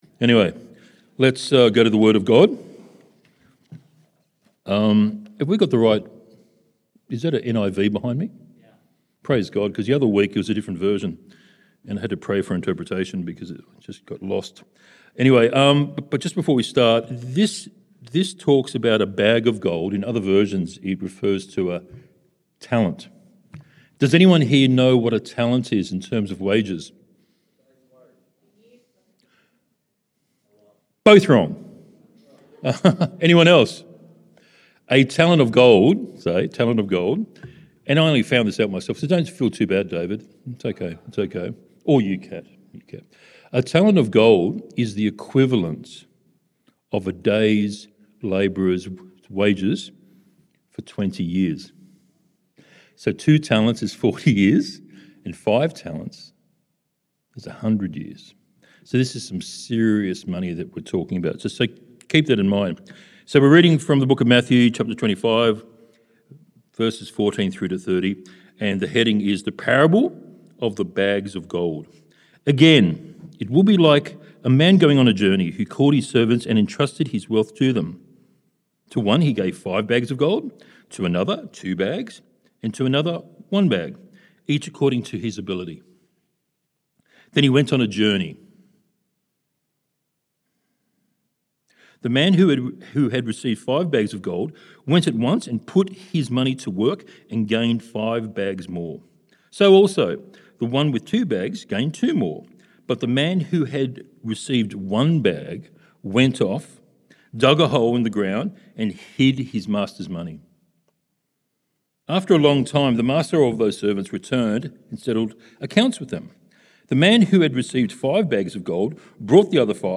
September Sermons